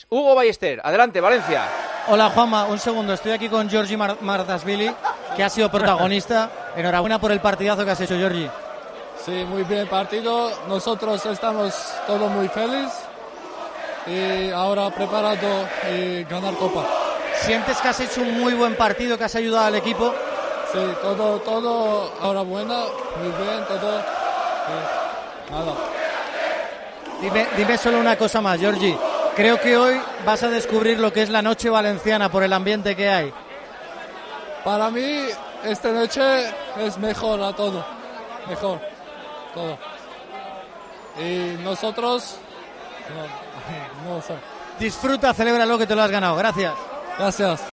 habla con el portero georgiano tras la clasificación para la final de Copa del Rey.